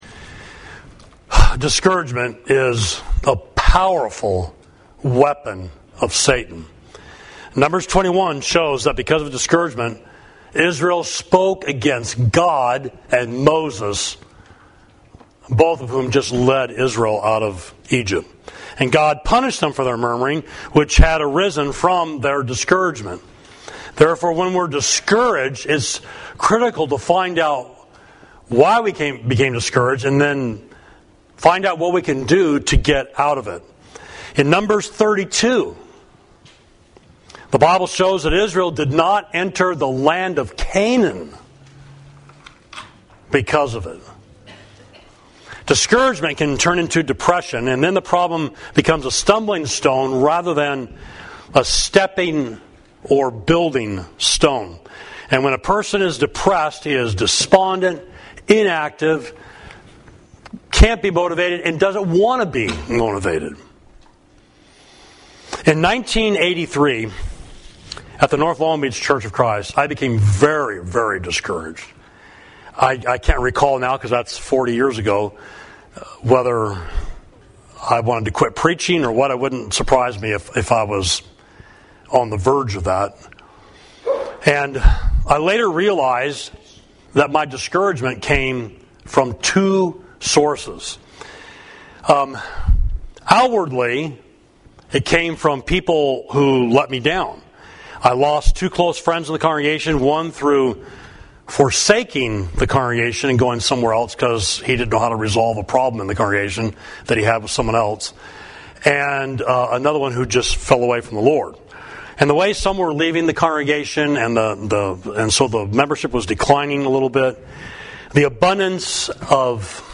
Sermon: Discouragement